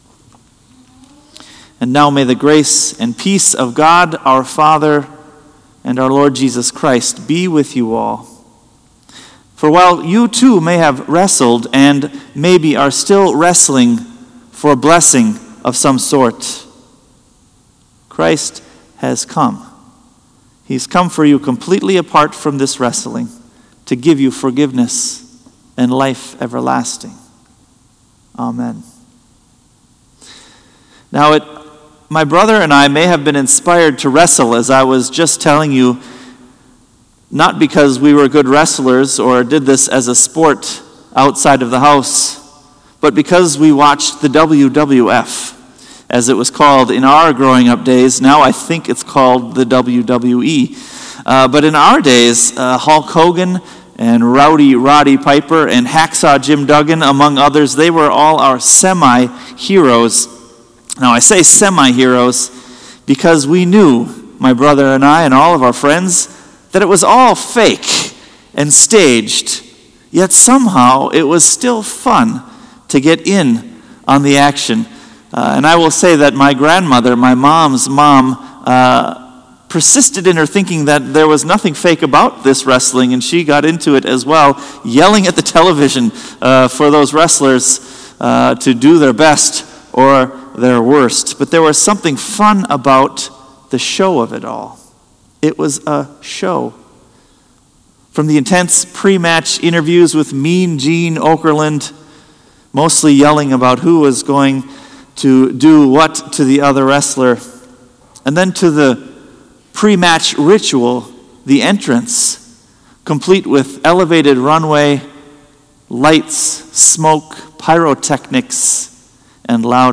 Sermon “Fighting for a Blessing”